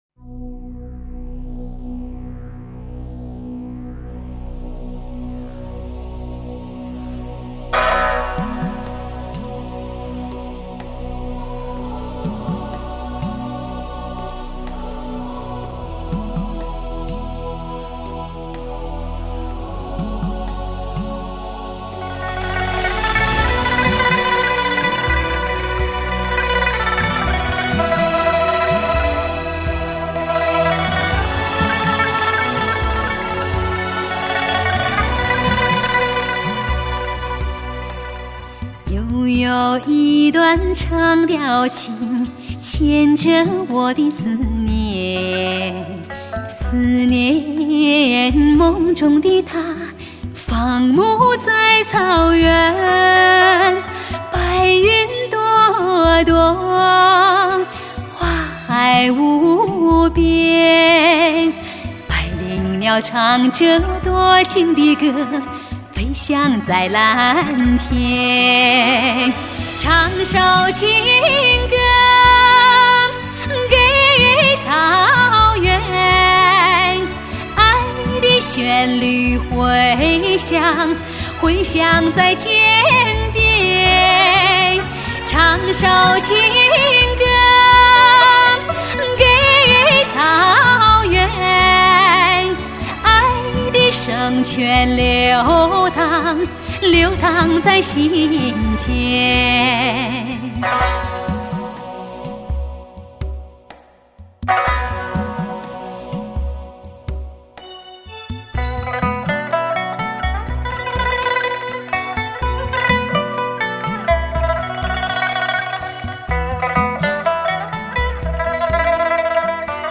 歌坛最响亮的名字 她的歌声给萎靡的歌坛一注强心剂 最温柔的情歌 最奔放的情怀 听！
此番深情款款的演唱，势必再次震撼你的心灵！